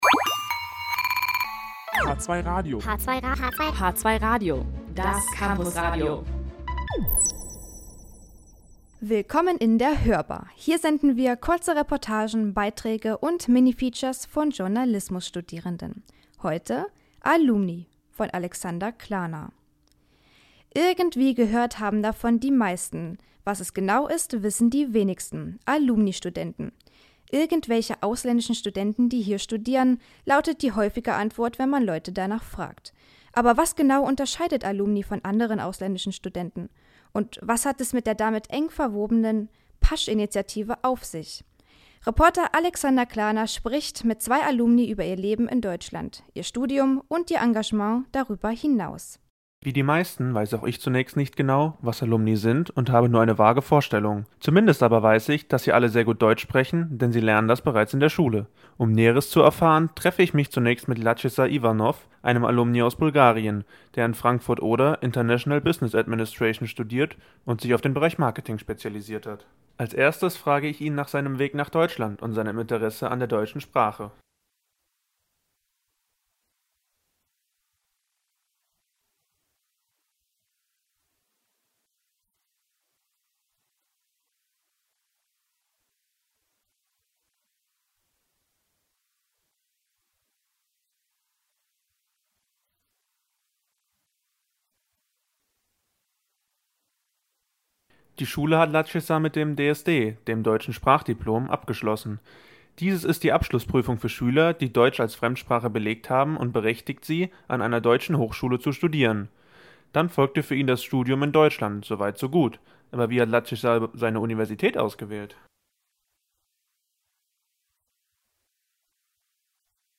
spricht mit zwei Alumni über ihr Leben in Deutschland, ihr Studium und ihr Engagement über das darüber hinaus.